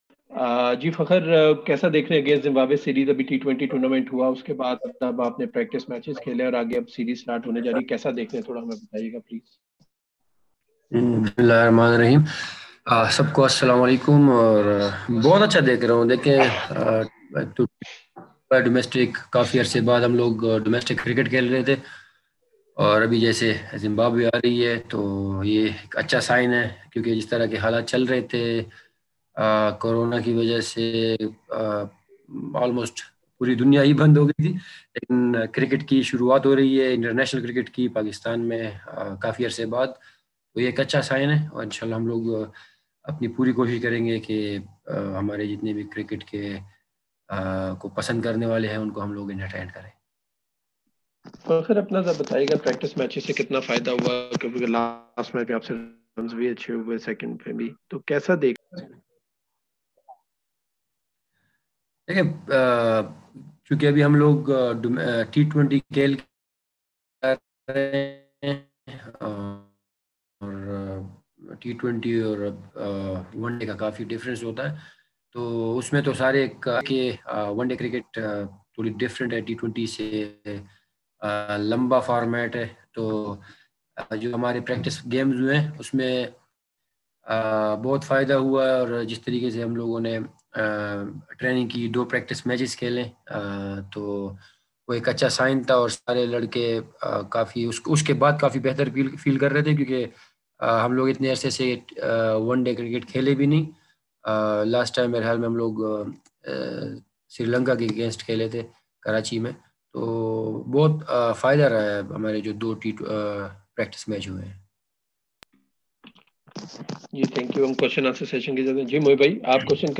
Pakistan batsman Fakhar Zaman and Zimbabwe all-rounder Wesley Madhevere held virtual media conferences with the local media today.